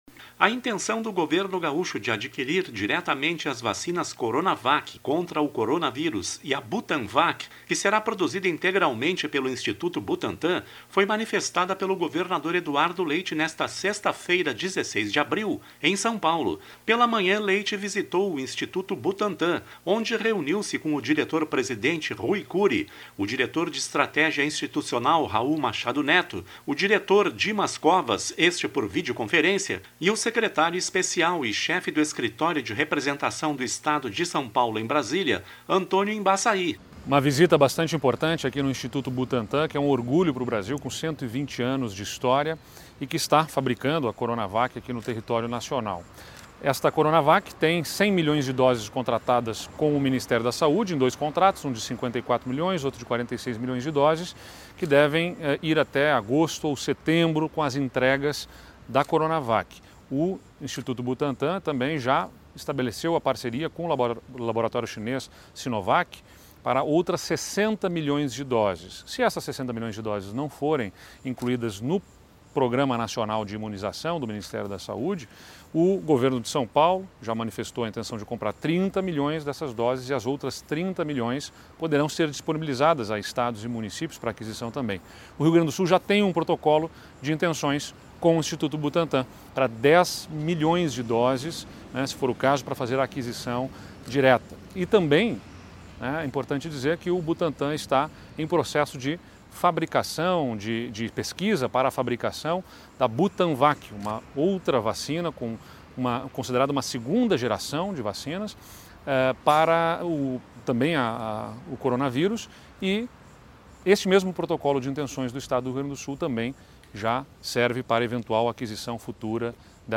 Em visita ao Instituto Butantan, em SP, o governador Eduardo Leite reforçou, nesta sexta (16/4), a intenção de adquirir diretamente as vacinas contra o coronavírus – tanto a Coronavac como a Butanvac, que será produzida integralmente pelo instituto.